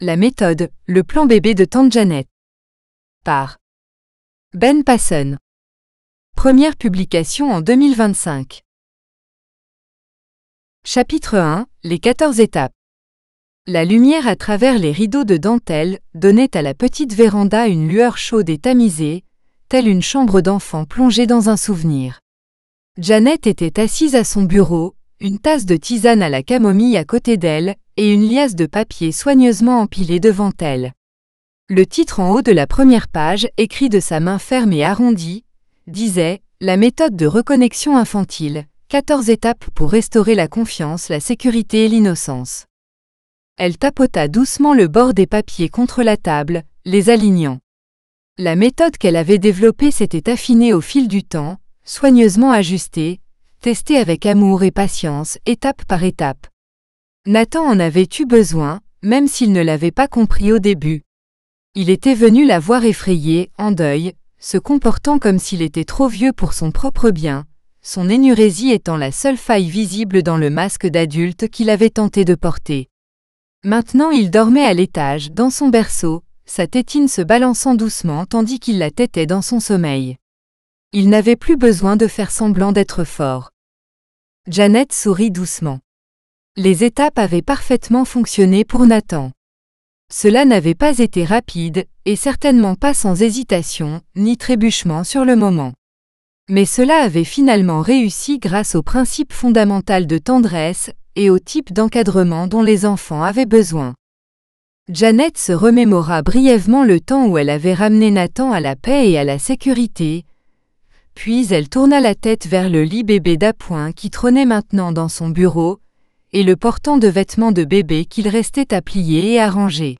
The Method FRENCH(female voice AUDIOBOOK): $US5.75